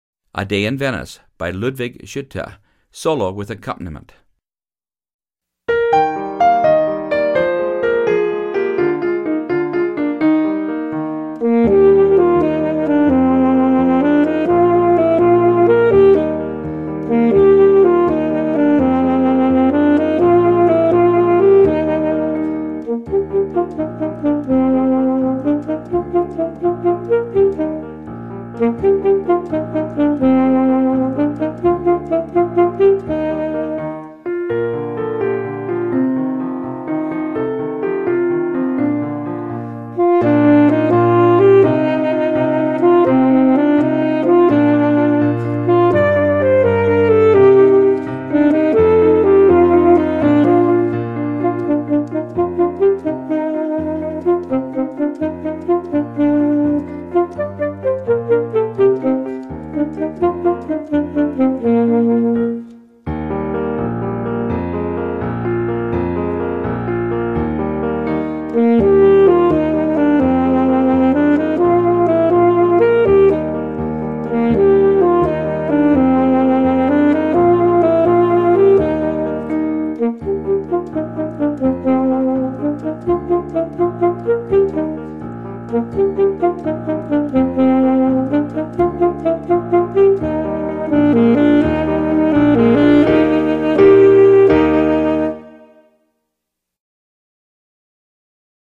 Solo with Piano Accompaniment – Performance Tempo
A-Day-in-Venice-Solo-and-Accomp.mp3